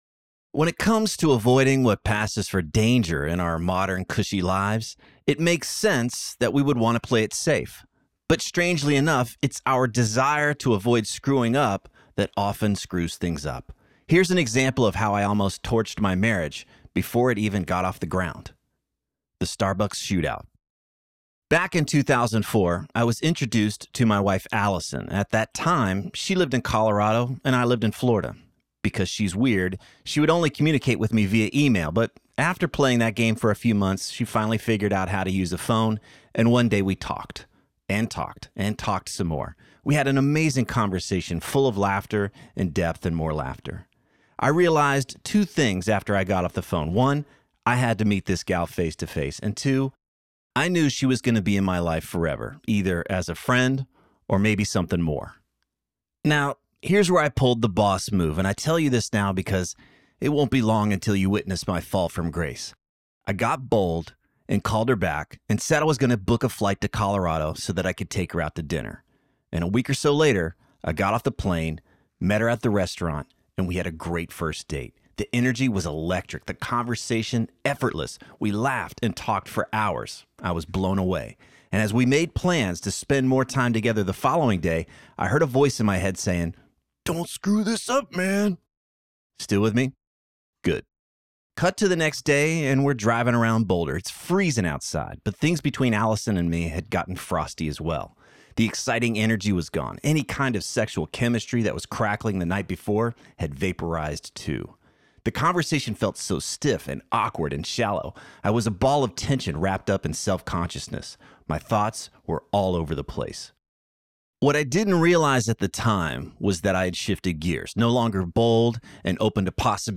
This Book Will Make You Dangerous Audiobook Sample